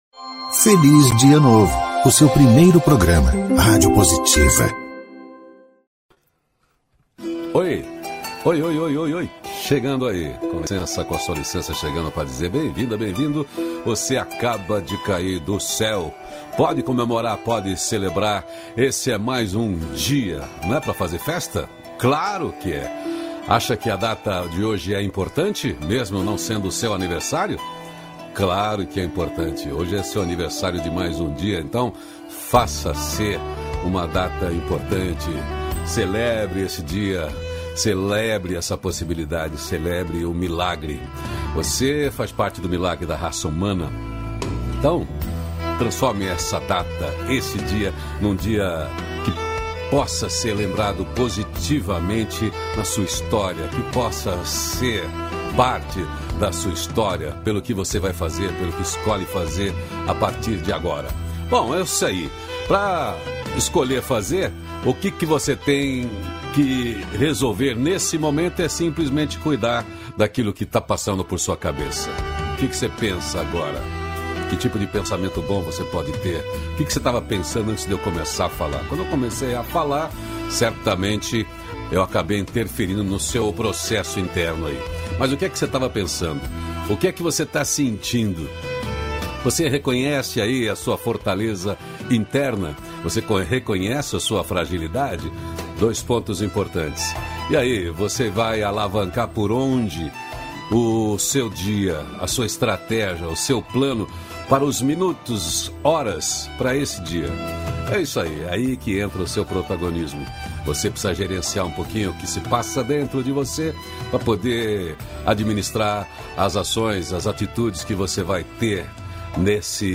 -500FelizDiaNovo-Entrevista.mp3